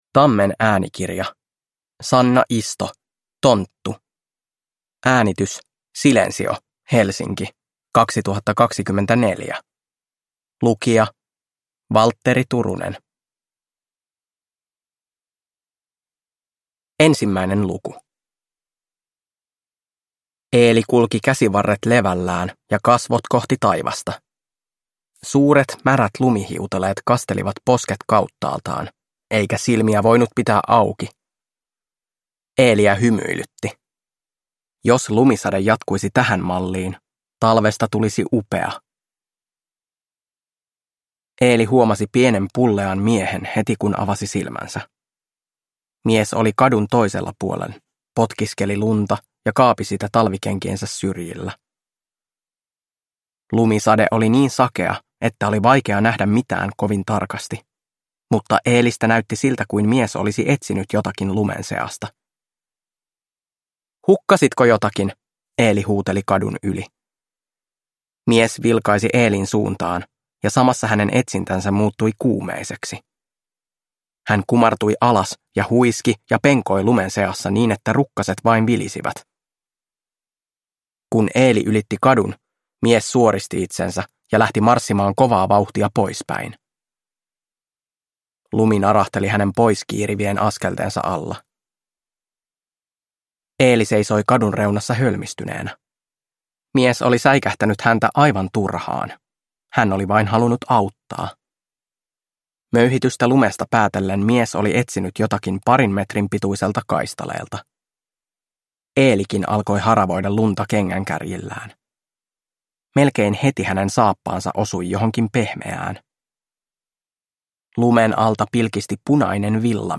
Tonttu – Ljudbok